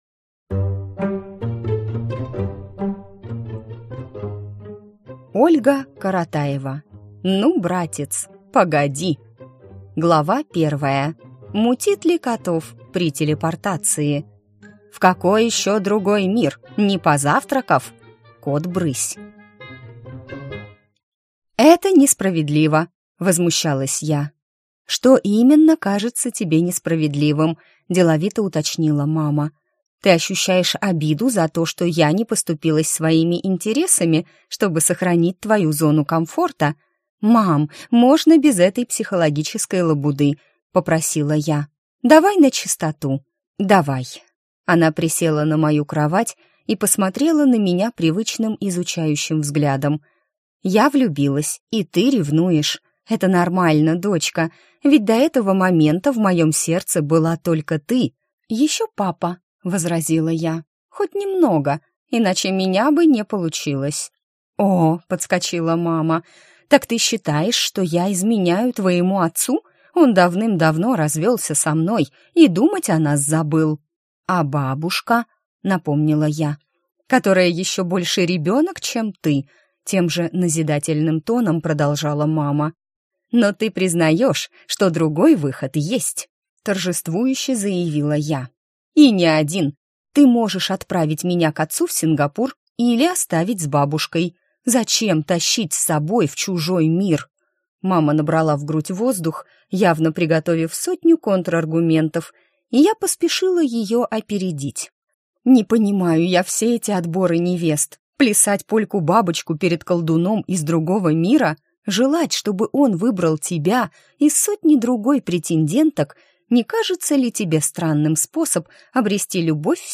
Аудиокнига Ну, «братец», погоди!